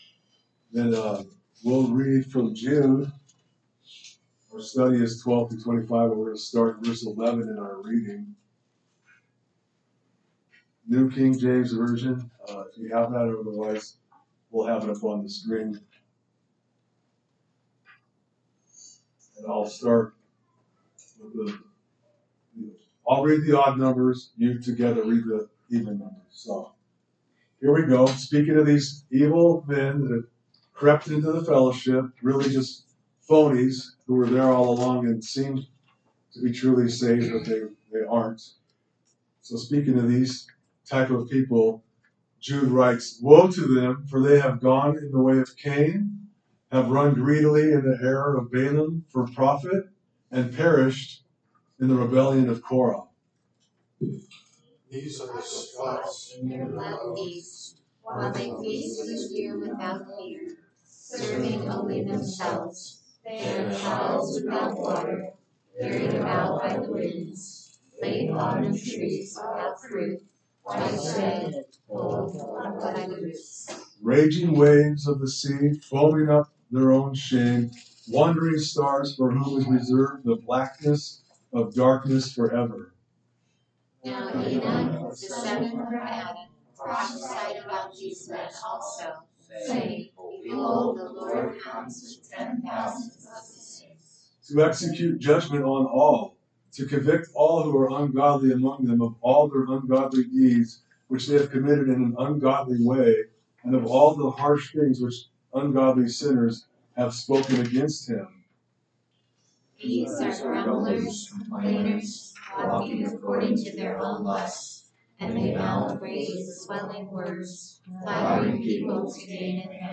Sermons - Calvary Chapel Ames